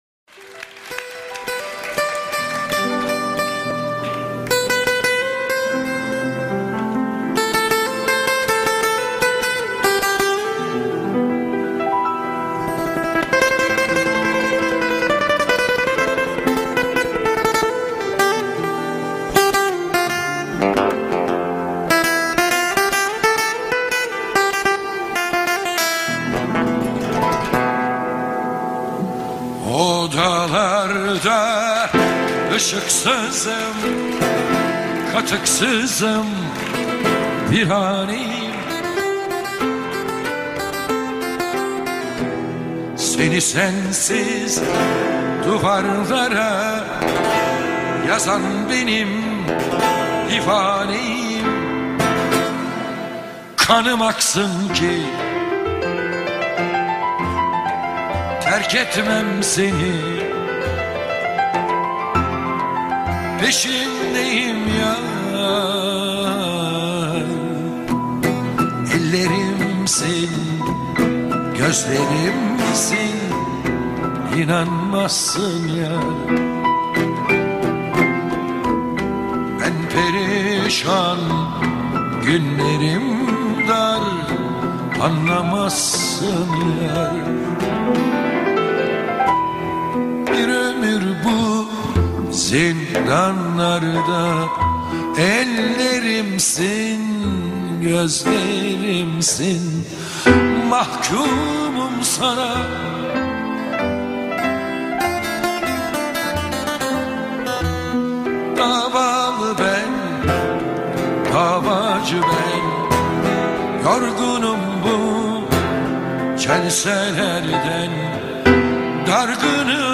موزیک ترکی